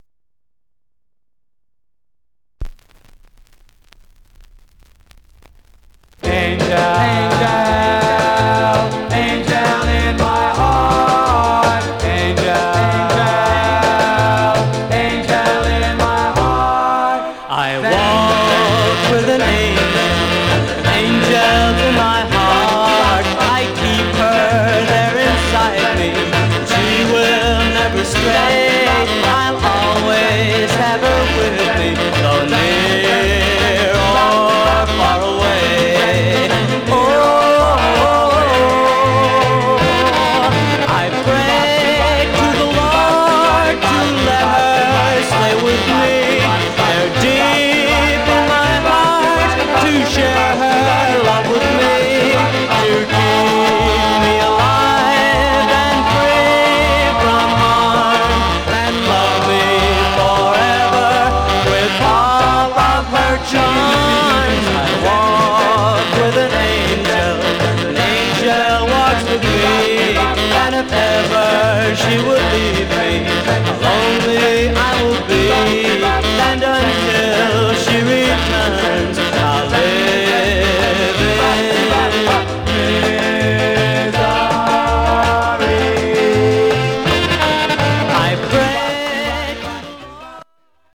Stereo/mono Mono
Surface noise/wear